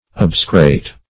Meaning of obsecrate. obsecrate synonyms, pronunciation, spelling and more from Free Dictionary.
Search Result for " obsecrate" : The Collaborative International Dictionary of English v.0.48: obsecrate \ob"se*crate\ ([o^]b"s[-e]*kr[=a]t), v. t. [imp.